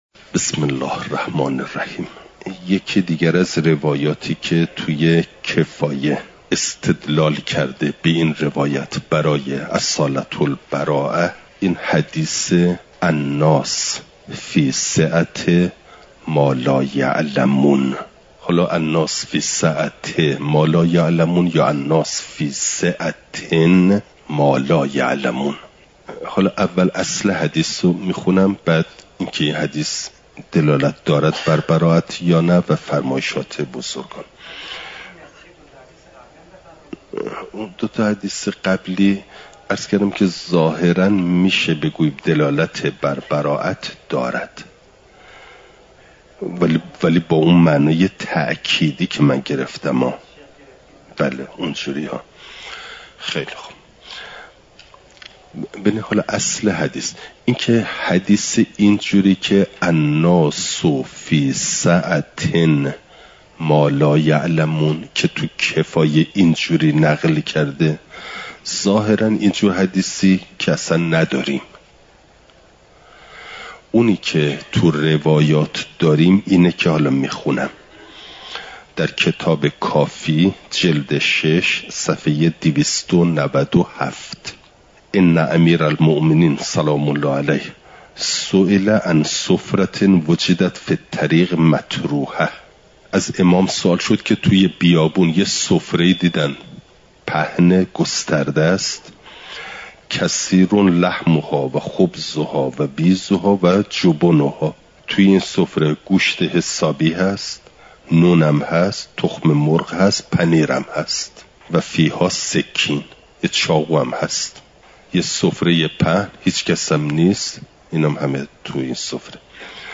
اصول عملیه؛ برائت (جلسه۴۱) – دروس استاد